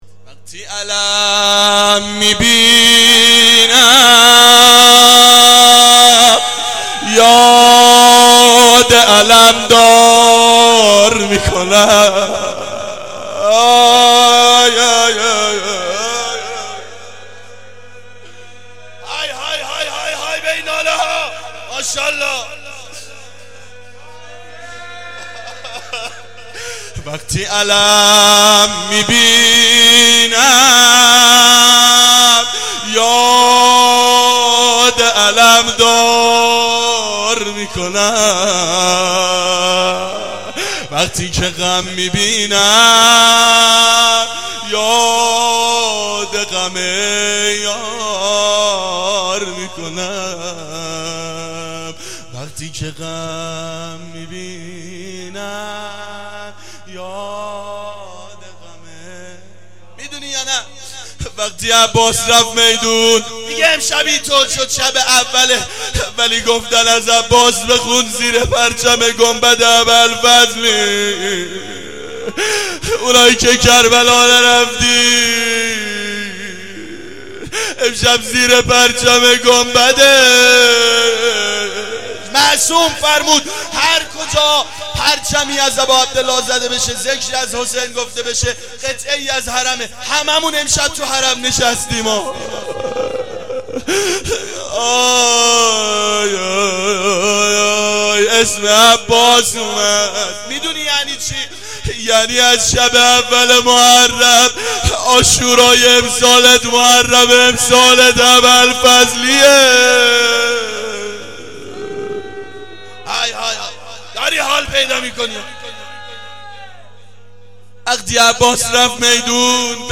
روضه حضرت عباس علیه السلام
شب اول محرم 89 گلزار شهدای شهر اژیه
0-روضه-حضرت-عباس-ع.mp3